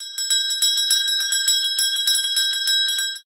BellSmall.ogg